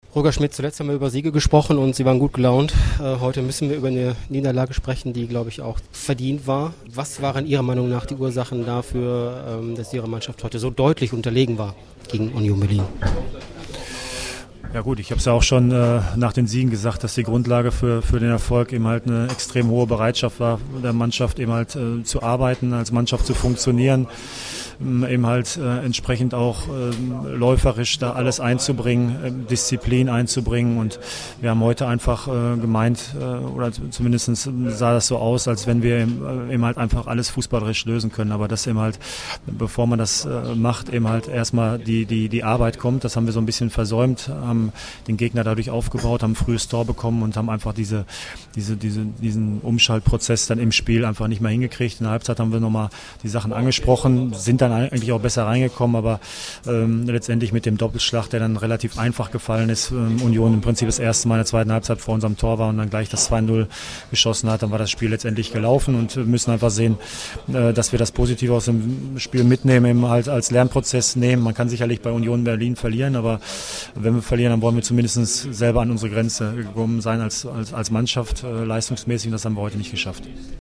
Audiokommentar
Chef-Trainer Roger Schmidt zum Spiel
Berlin_SCP_Interview_Schmidt.mp3